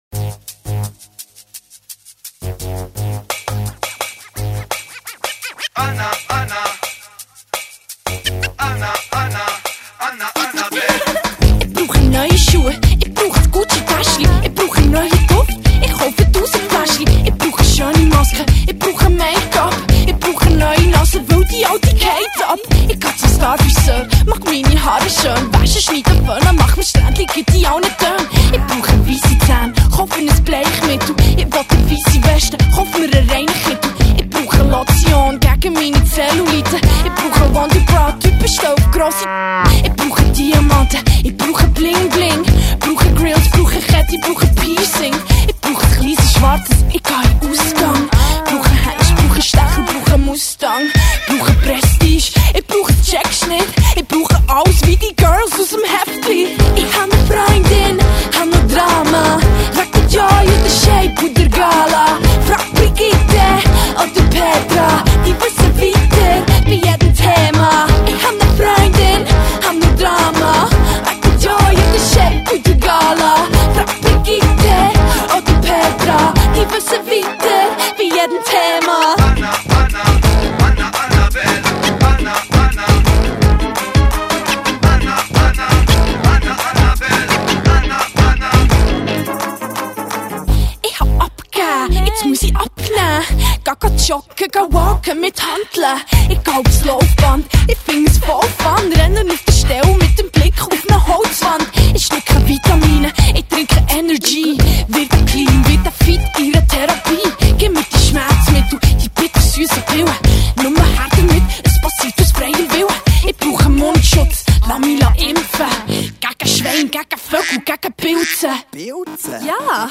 female rapper
songs are written in the dialect